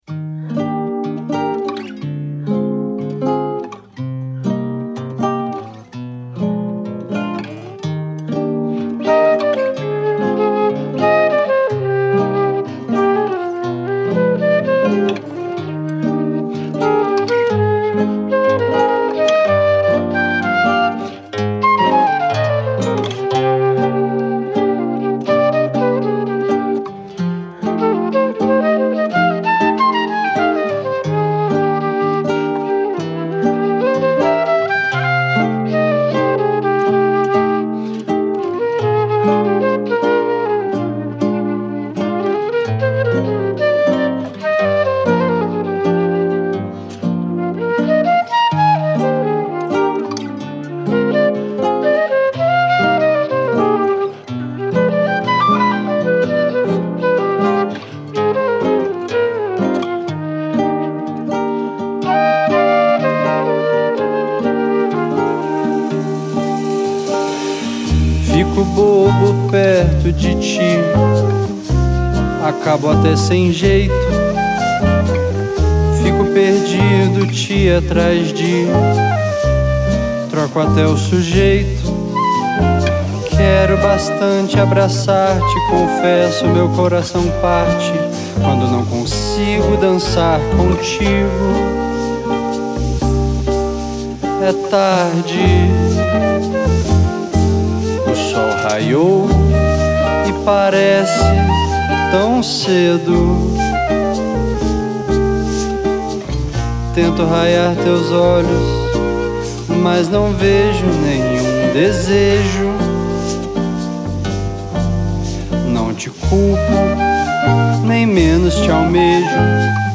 EstiloBossa Nova